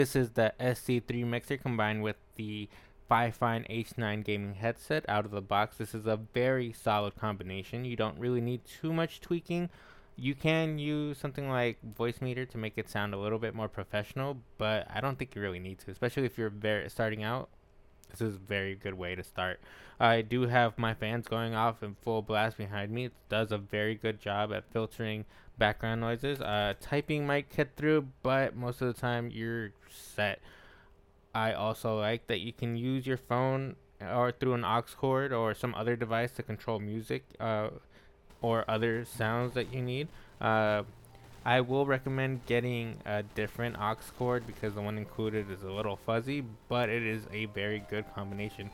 I used the mixer with the Fifine H9 gaming headset and am very impressed with the sound quality I got.
sc3voicecheck.mp3